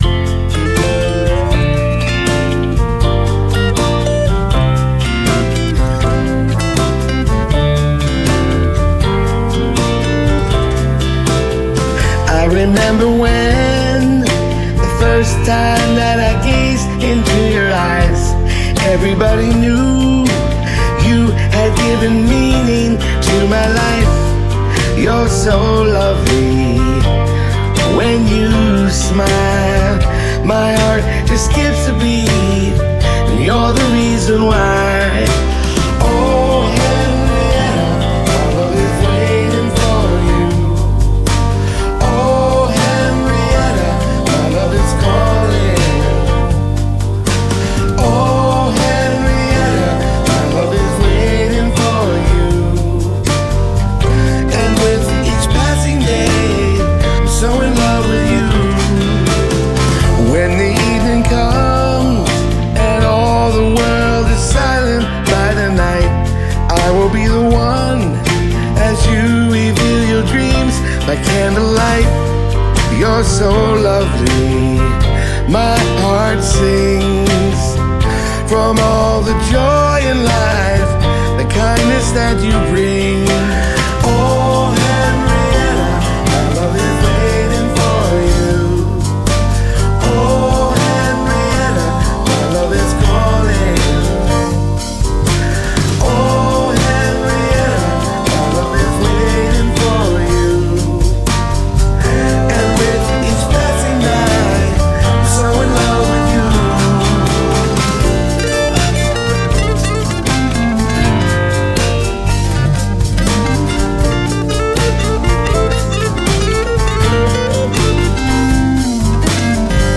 the original ballad.
Categories: Pop